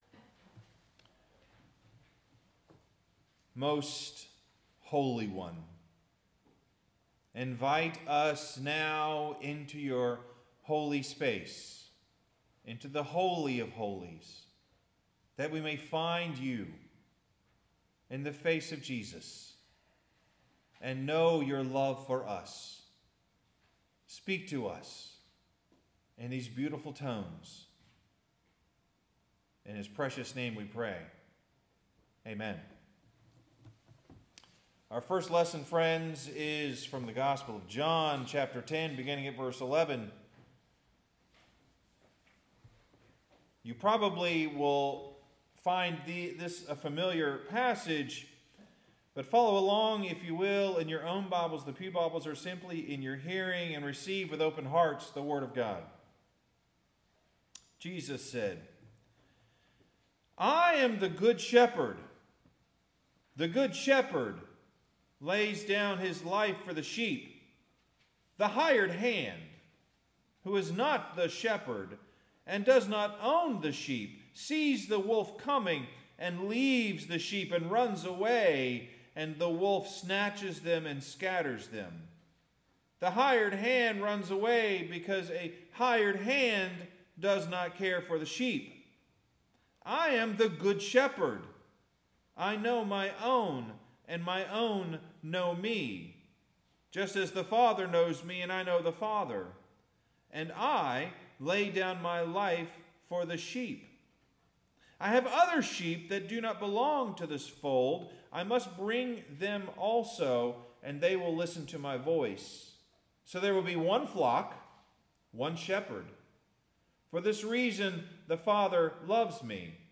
Some time you will have to ask me about one of my favorites – “confidence,” but eventually when I write my book about this called “Words of Faith,” chapter one will all be about today’s sermon: shepherd and sheep. The actual Latin word for shepherd is pastor.